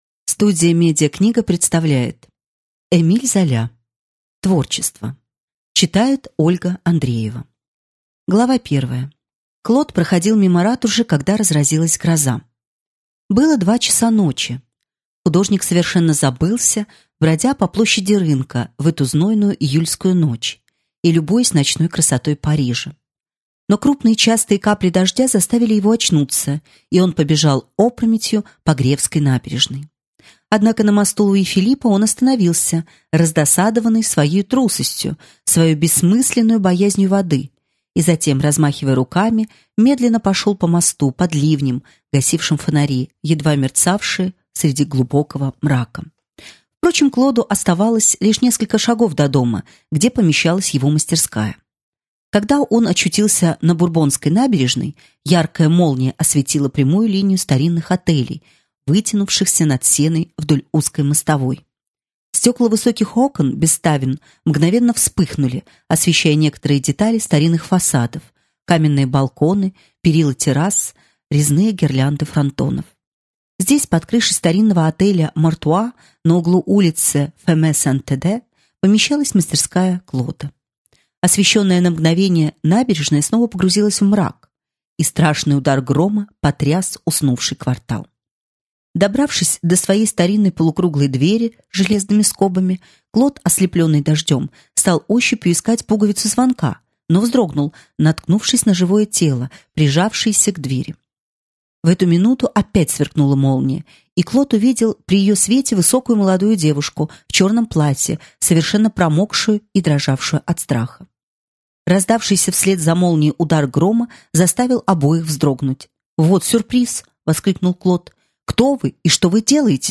Аудиокнига Творчество | Библиотека аудиокниг